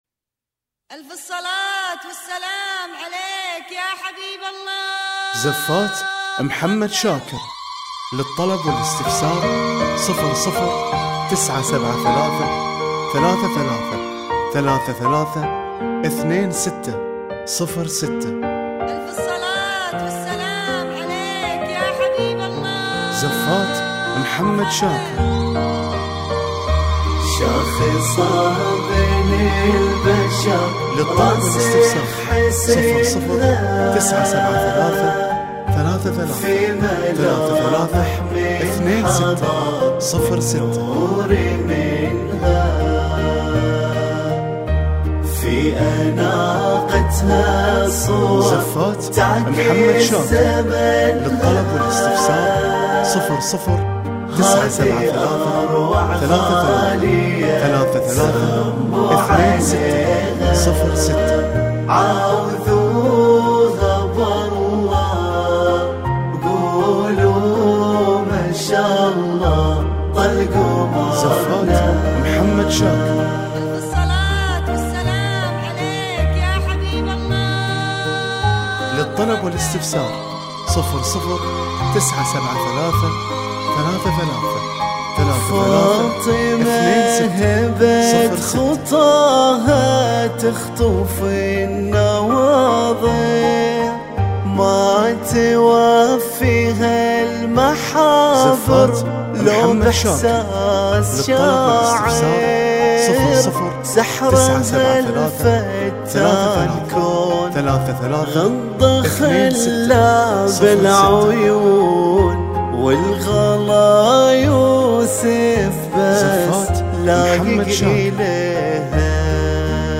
وتتوفر بالموسيقى وبدون موسيقى